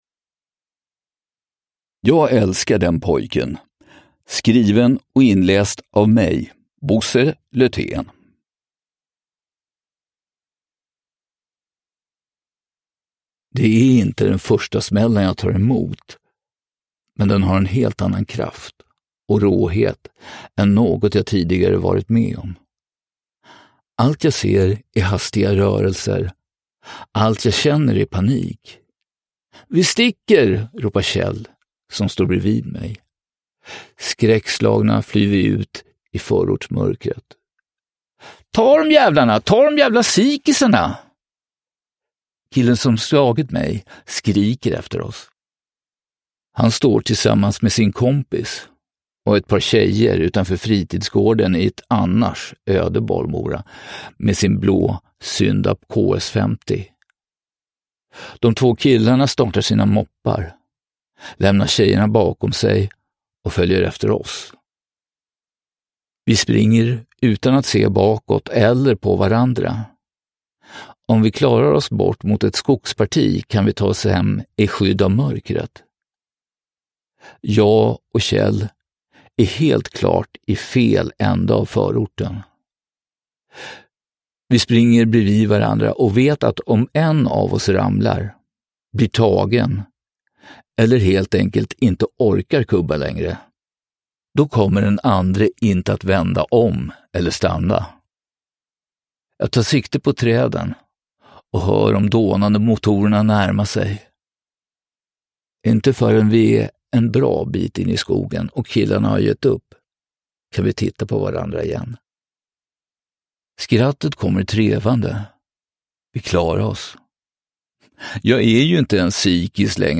Jag älskar den pojken – Ljudbok – Laddas ner